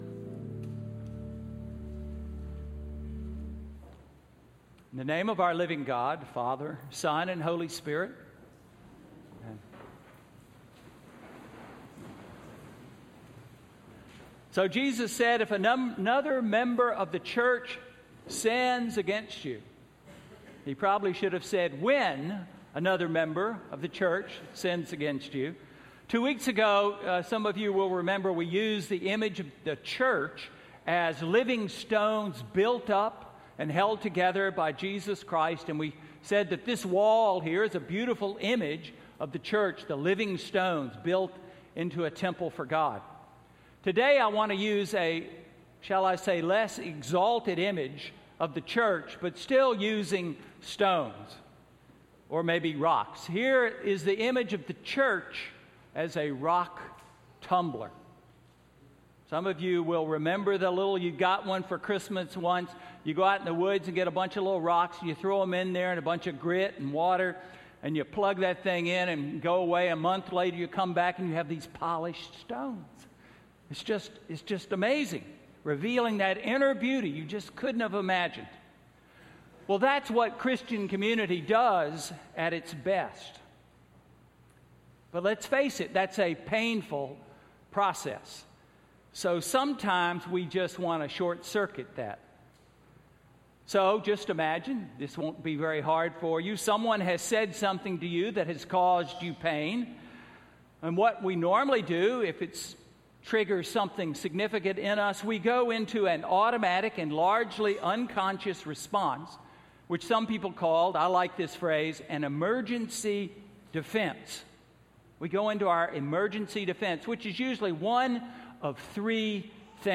Sermon–September 7, 2014